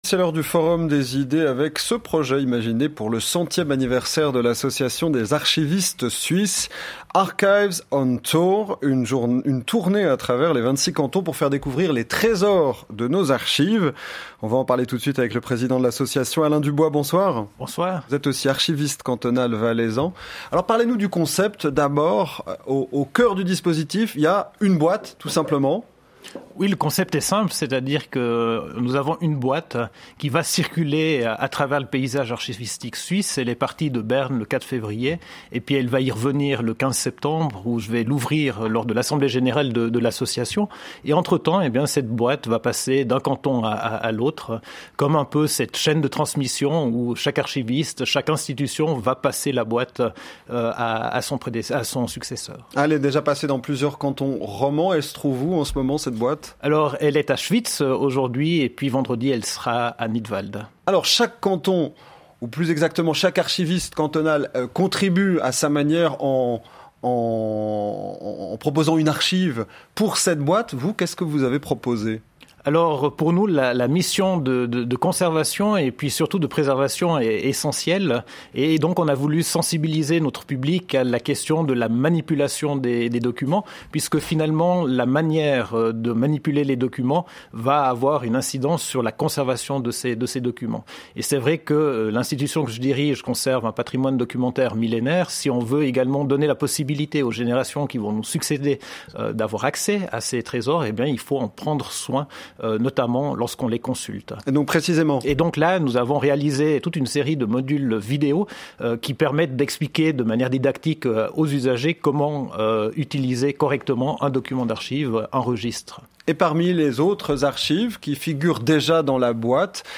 Homme parlant
Interview radiophonique